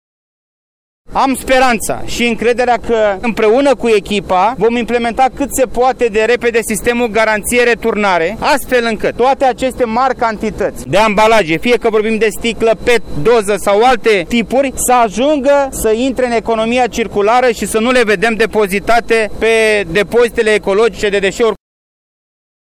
Este apelul pe care l-a făcut ministrul Mediului, Costel Alexe, aflat în vizită, la Brașov, la Depozitul Ecologic de Deșeuri, unde a vizitat un front de lucru de 2500 demetri la celula 3, în care se depozitează gunoaiele adunate de la populație.